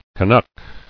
[Ca·nuck]